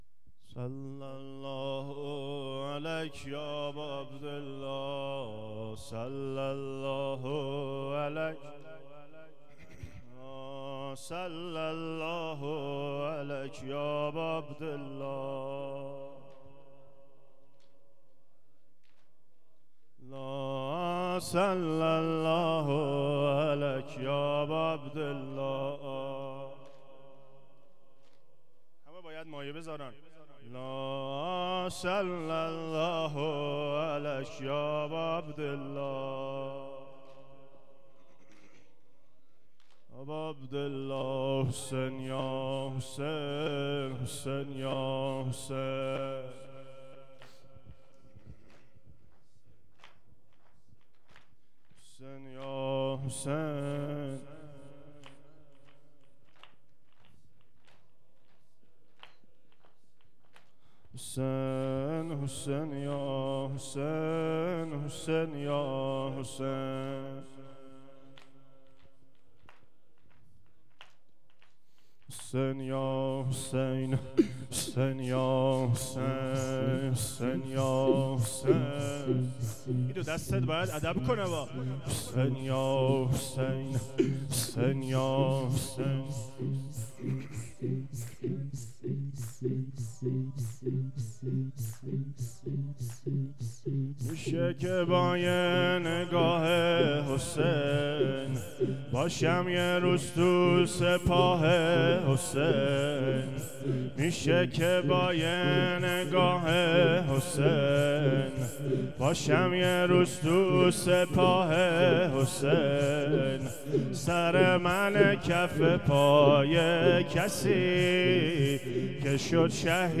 زمینه شب چهارم